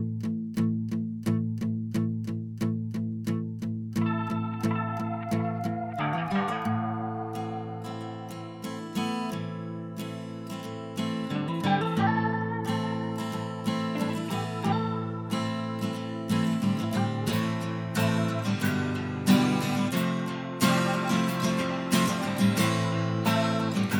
Comedy/Novelty